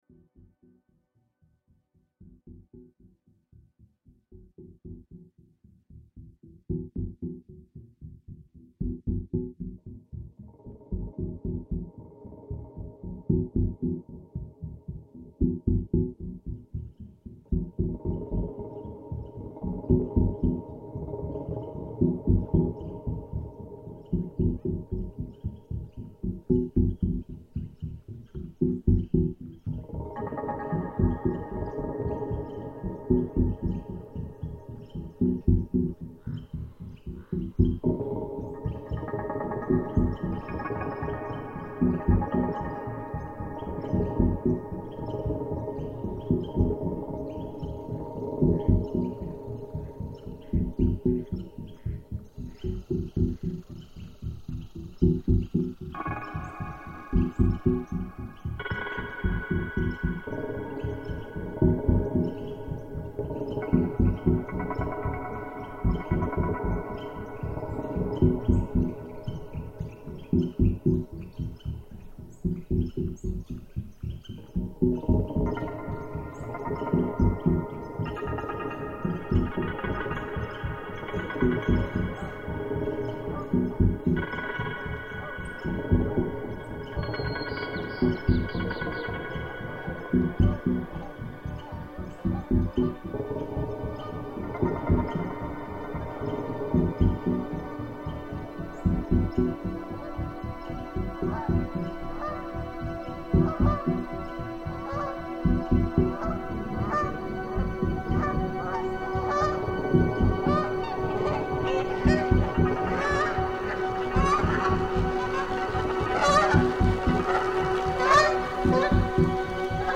Chipmunk Creek reimagined